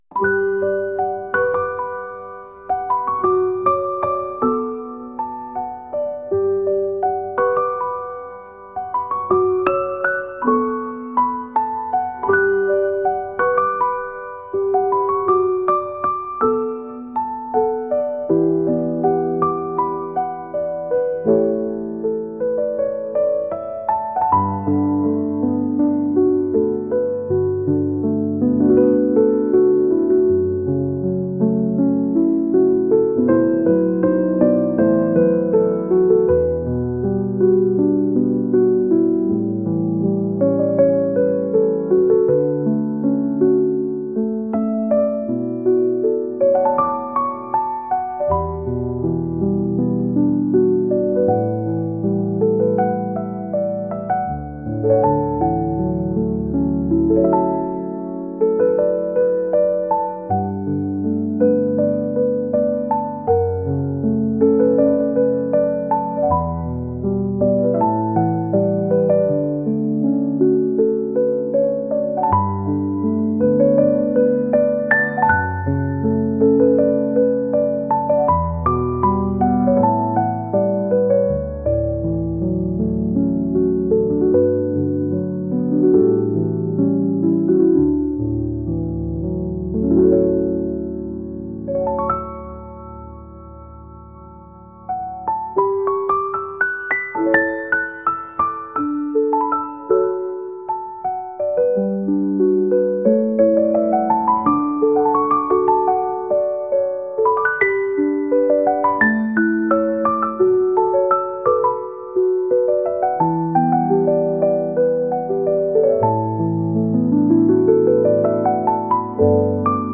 失敗した時に聞くピアノ曲です。